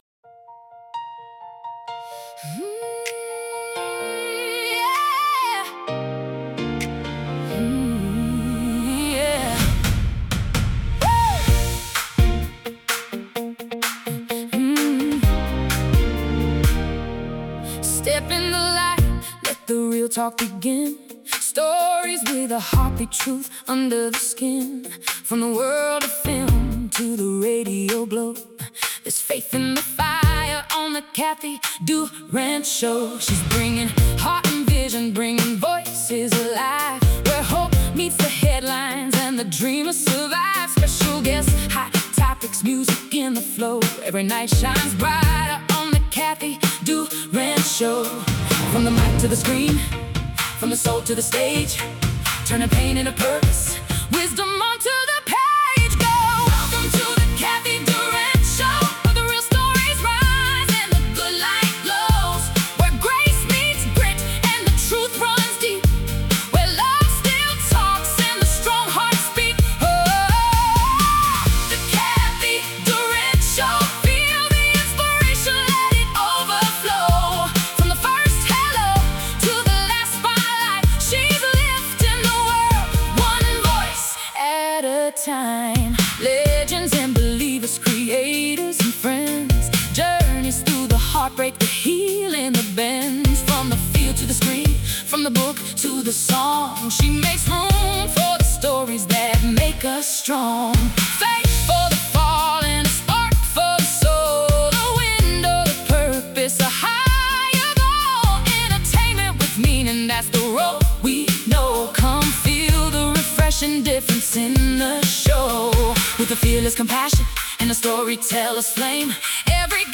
Music Clip
Dramatic Emotional Inspirational Motivational